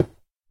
stone3.ogg